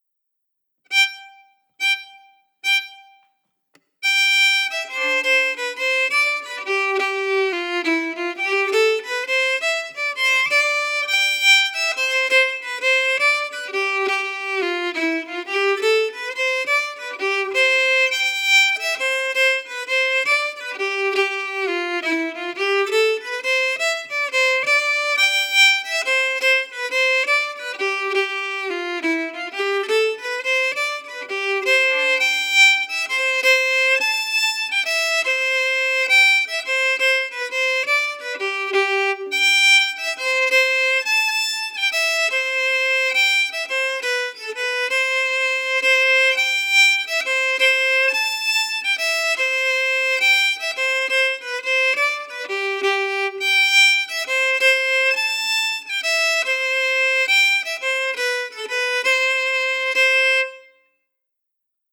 Key: C
Form: Québecois six-huit (Jig)
Genre/Style: Québecois six-huit
Quadrille-Beauharnois-slow.mp3